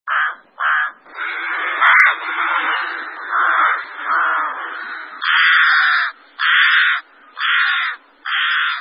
La Corneille noire